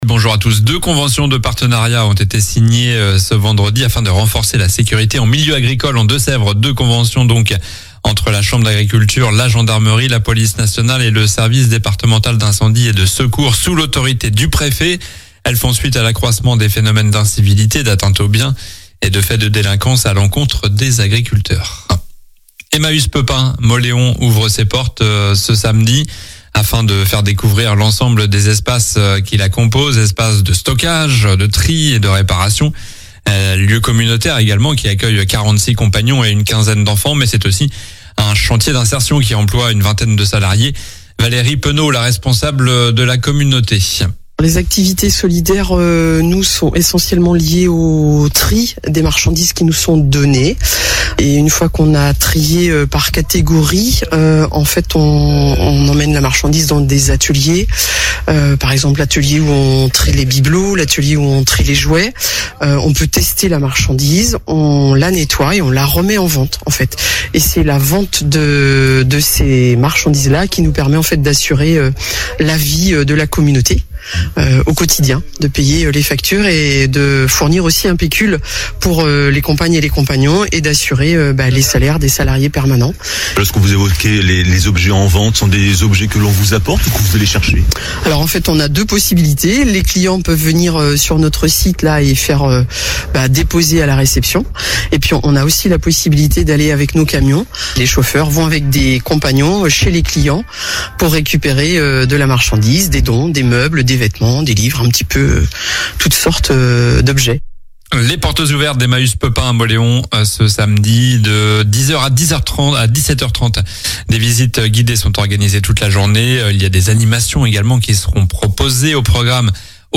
Journal du samedi 18 octobre (matin)